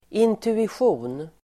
Ladda ner uttalet
intuition substantiv, intuition Uttal: [intuisj'o:n] Böjningar: intuitionen, intuitioner Synonymer: föraning, känsla, magkänsla Definition: förmåga att snabbt förstå och förutse något utan att känna till alla fakta